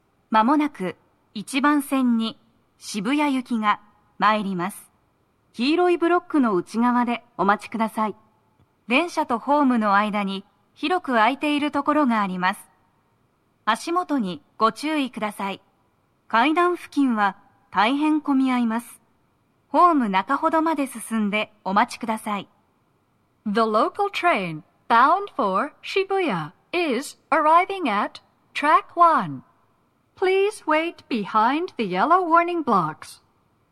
鳴動は、やや遅めです。
1番線 渋谷方面 接近放送 【女声
接近放送2